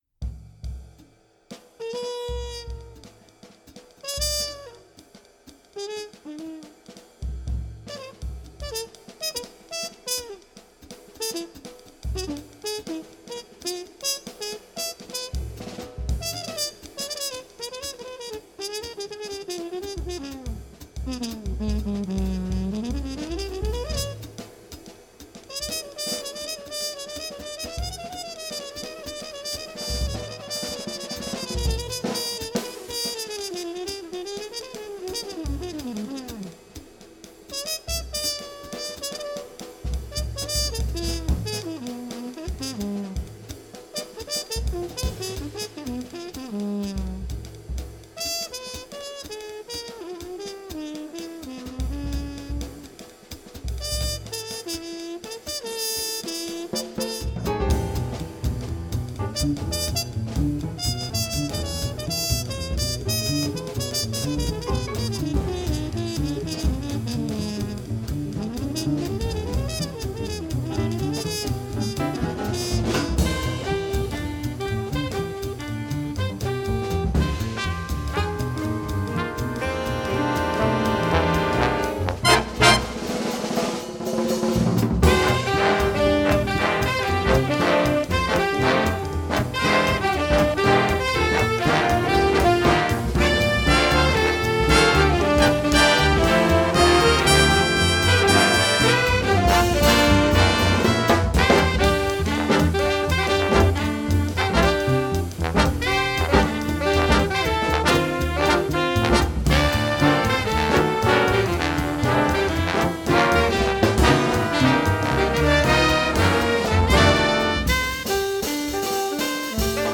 during a live recording session in San Francisco, California
Bass Trombone
Guitar
Drums
audio is courtesy of the band’s studio recording.